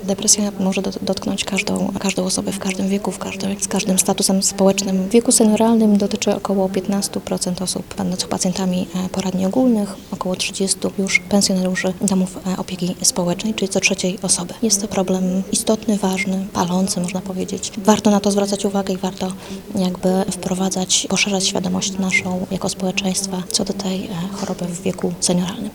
mówi specjalista psychologii klinicznej